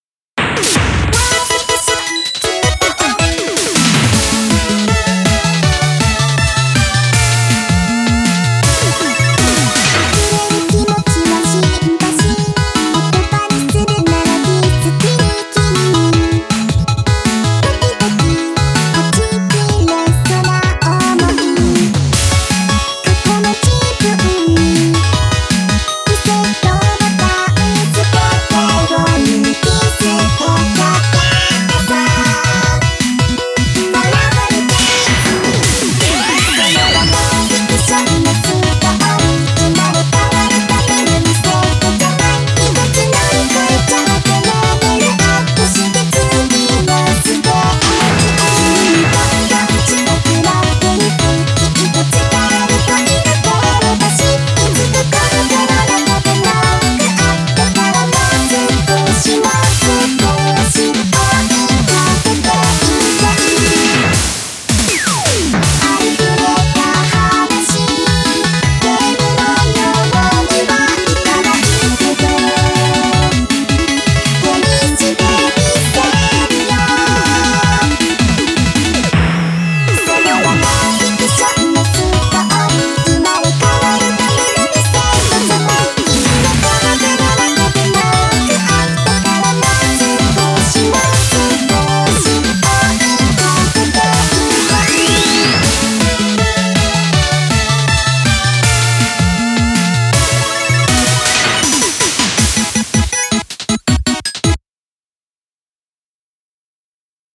BPM160
Audio QualityPerfect (High Quality)
Genre: TECHNO POP.
A quite catchy chiptune pop song